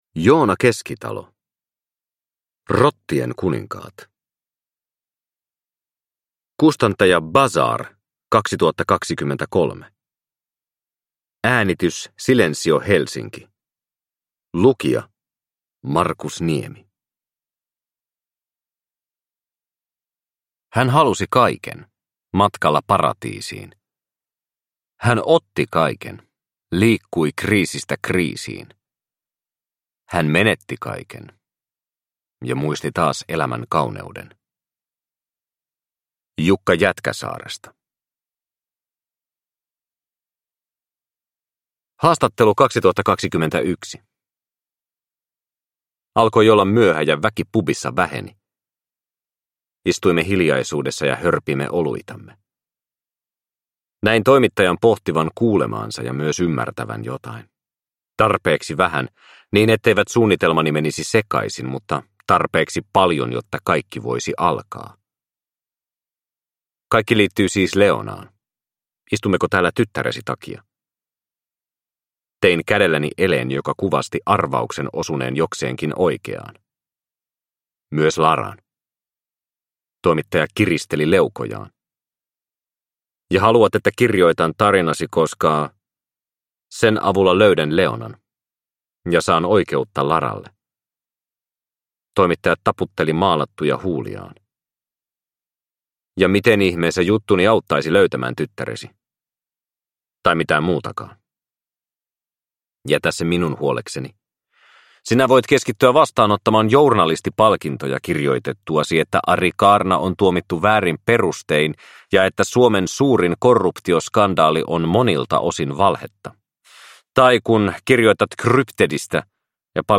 Rottien kuninkaat – Ljudbok – Laddas ner
Uppläsare: